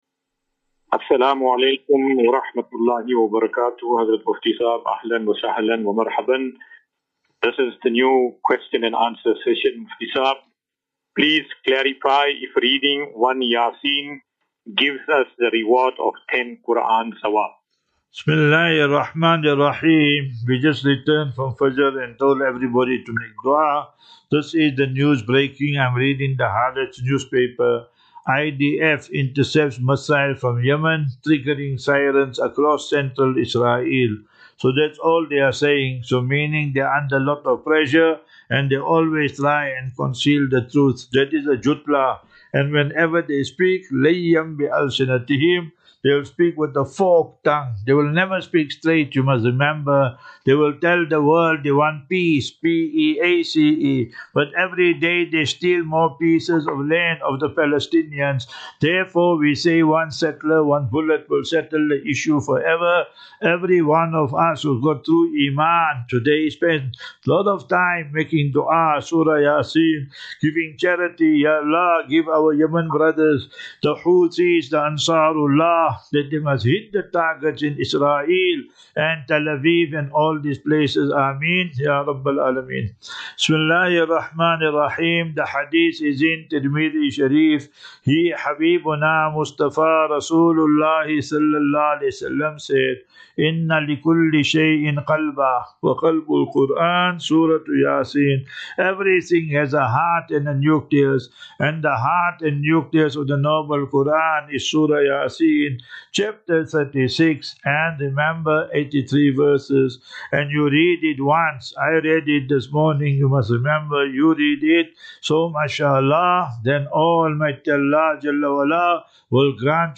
View Promo Continue Install As Safinatu Ilal Jannah Naseeha and Q and A 20 Mar 20 March 2025.